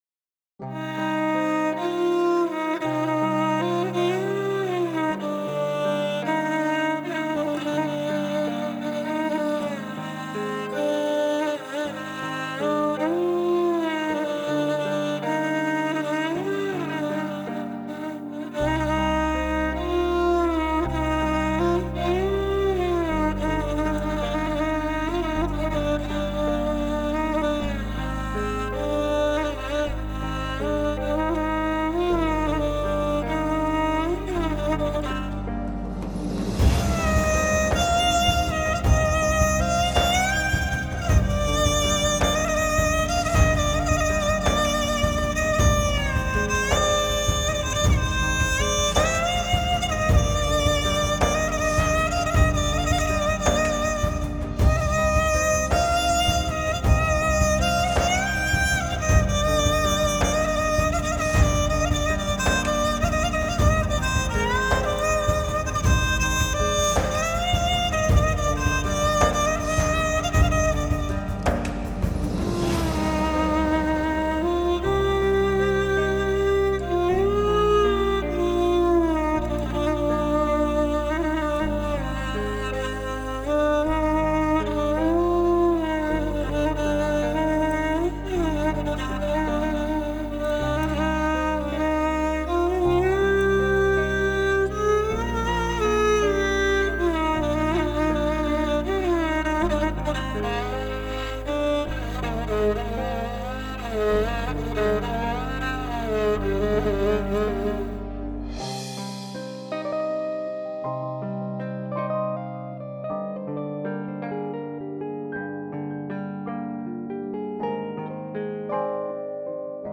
موسیقی بی کلام , عاشقانه , عصر جدید